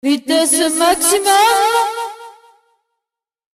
Vitesse maximum (Voix foraine)